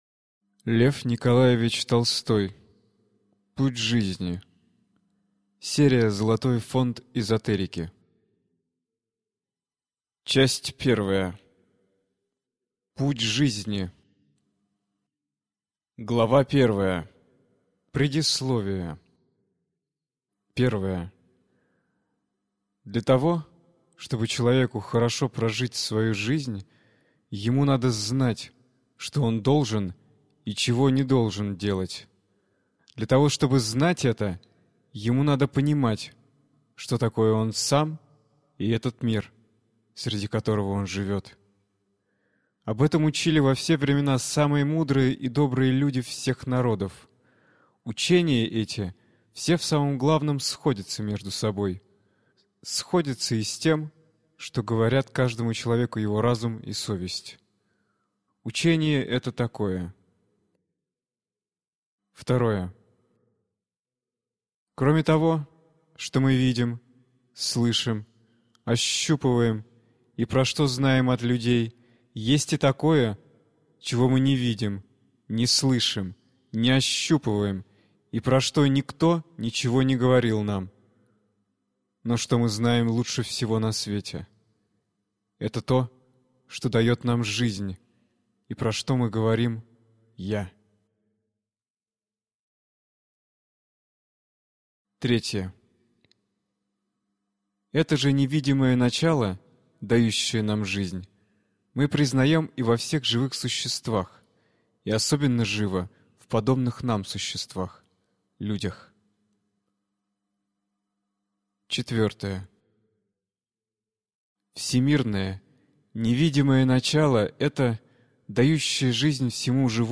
Аудиокнига Путь жизни - купить, скачать и слушать онлайн | КнигоПоиск